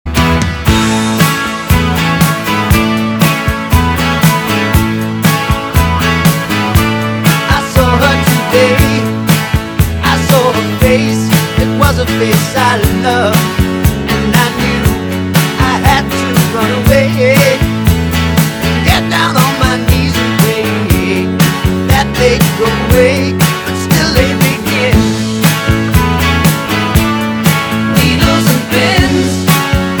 • Качество: 320, Stereo
громкие
Soft rock
ретро
Glam Rock